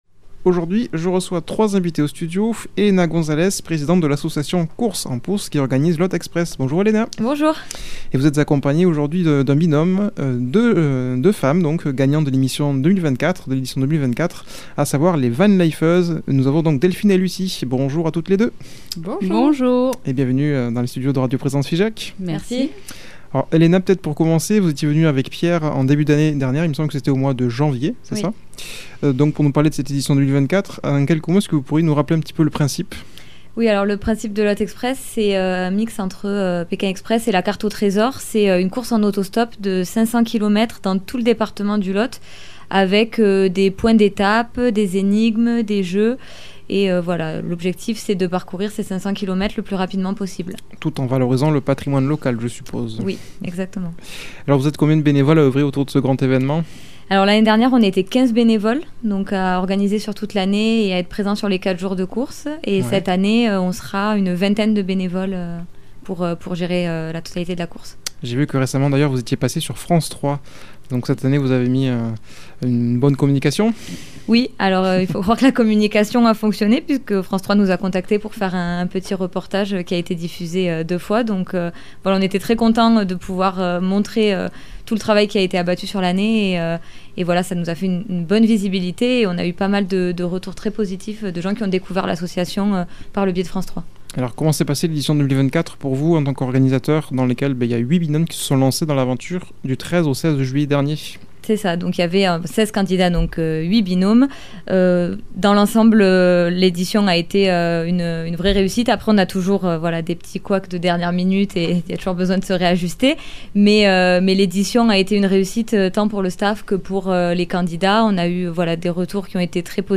invitées au studio